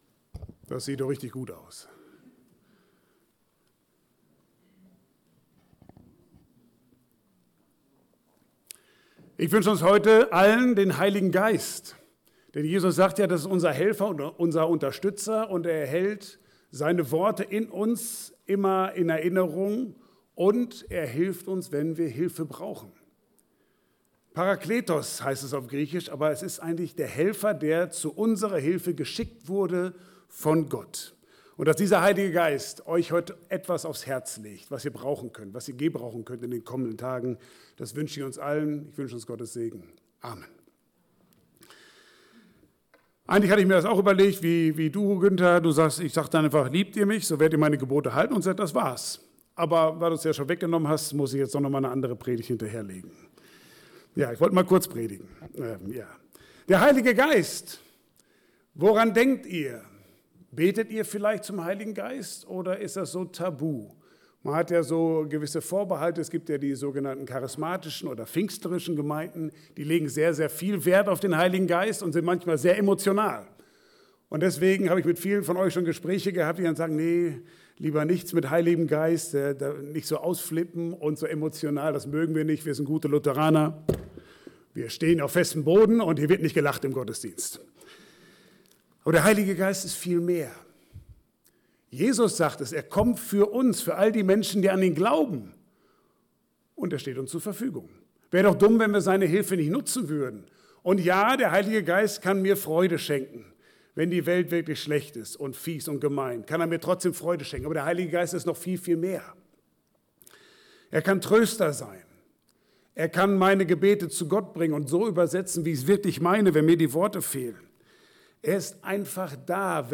Passage: Johannes 14, 15-27 Dienstart: Gottesdienst « Ein Leben ohne Salz kann Ich mir nicht vorstellen.